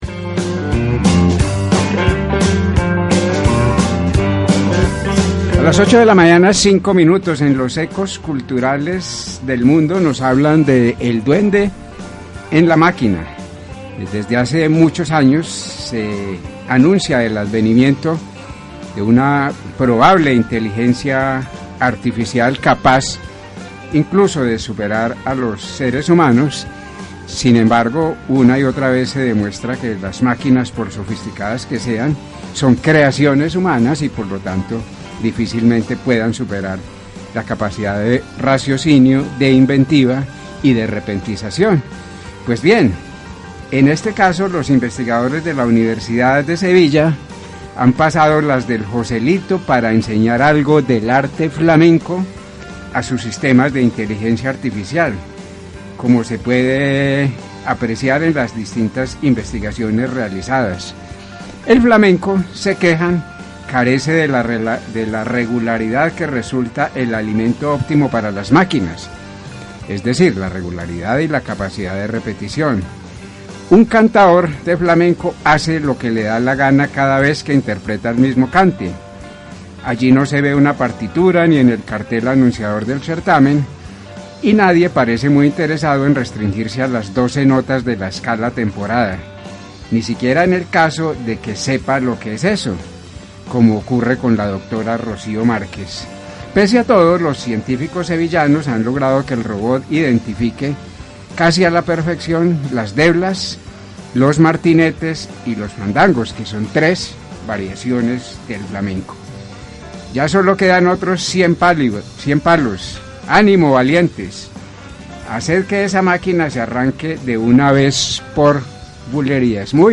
Entrevista en Ecos 1360 – La Astilla en el Ojo – Novena Temporada Creativa
Compartimos con ustedes esta entevista realizada el lunes 7 de mayo de 2018 en la Emisora Ecos 1360, en donde socializamos algunas características de la Temporada Creativa 2018: Surreal